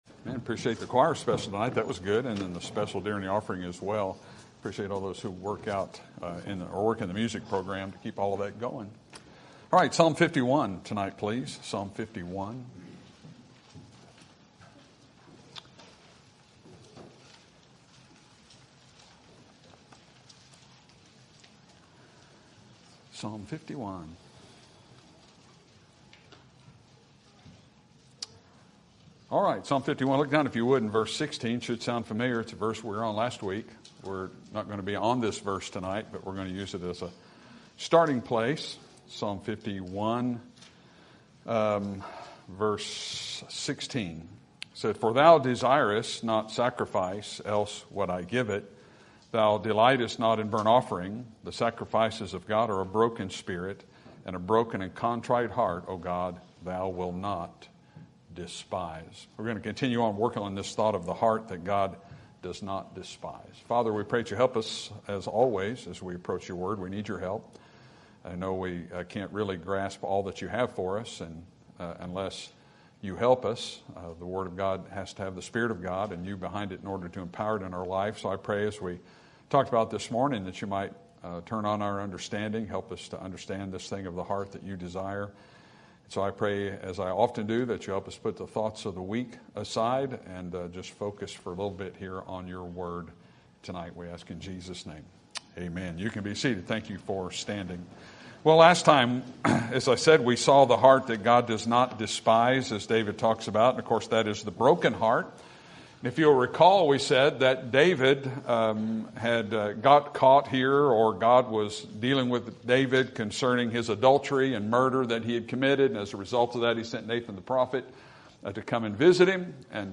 Sermon Topic: General Sermon Type: Service Sermon Audio: Sermon download: Download (23.32 MB) Sermon Tags: Psalm Heart Broken Emotional